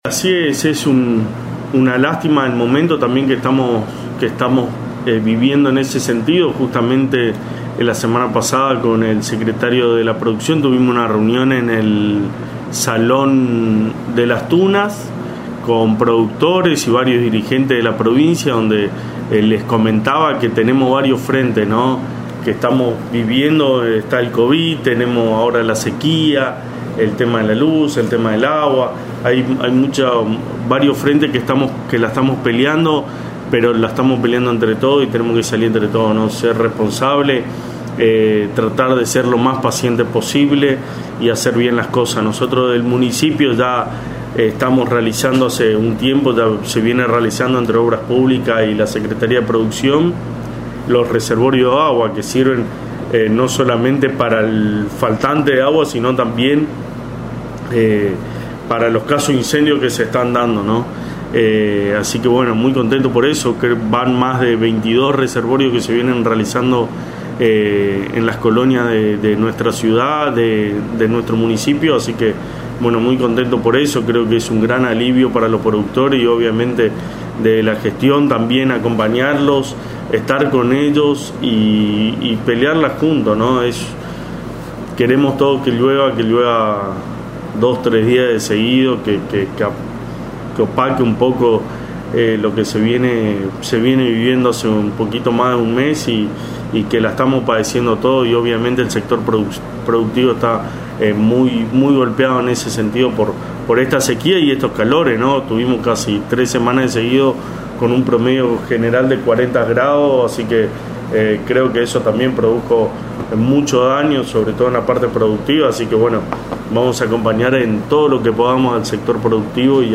El secretario de Gobierno Gastón Casares brindó detalles de los diferentes trabajos que realizan para la creación de “Reservorios De Agua” en la colonia, con labores en conjunto de las secretarías de Obras Públicas y Producción, buscando solucionar el problema hídrico, tanto para colonos como para los bomberos que regularmente la necesitan para combatir incendios en la zona.